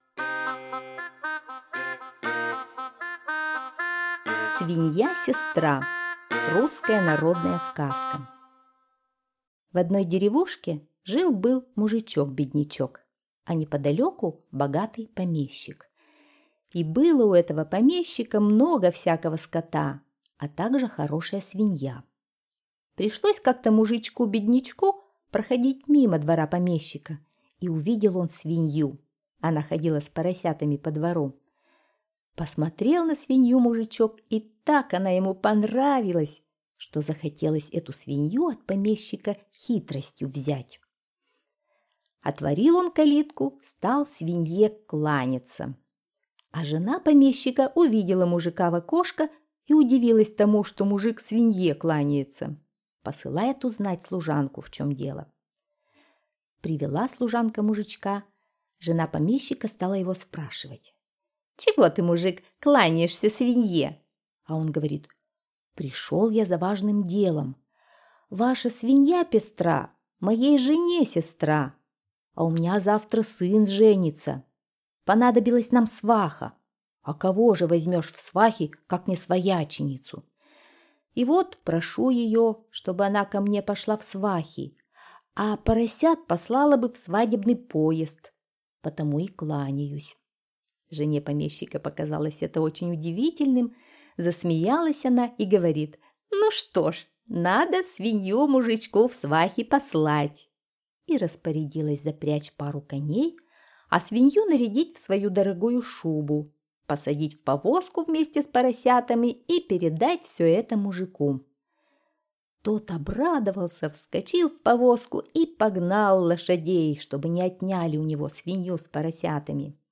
Свинья-сестра - русская народная аудиосказка - слушать онлайн